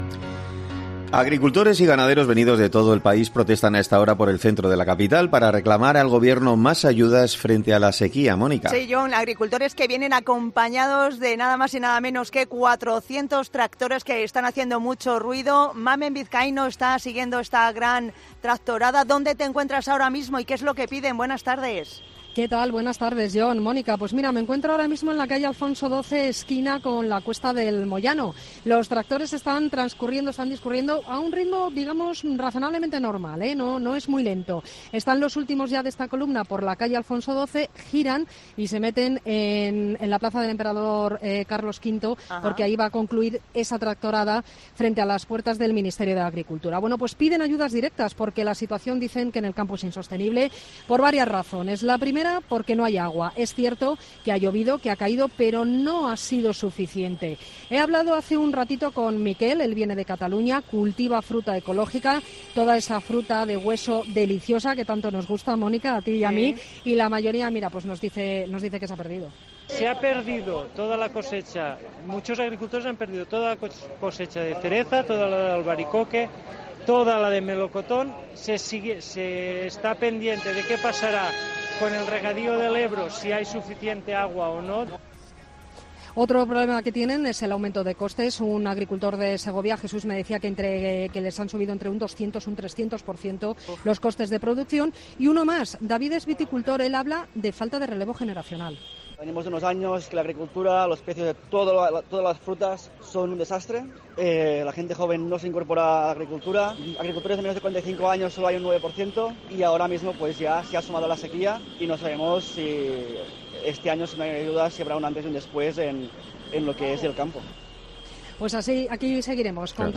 entrevista a varios agricultores en la tractorada de Madrid